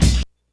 kick03.wav